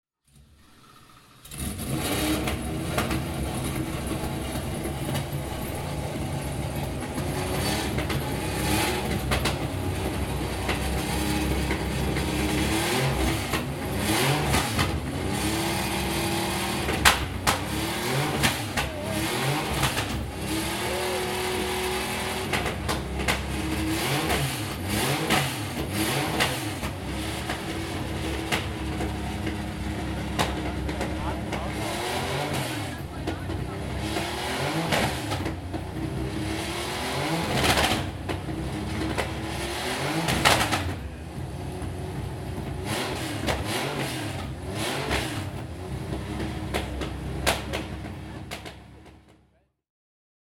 5th Porsche Sound Night 2015 - when racing legends wake up and scream (Event Articles)
Porsche 911 Carrera RSR 2.8 (1973) - Targa-Florio-Auto - Laufgeräusch